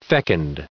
1760_fecund.ogg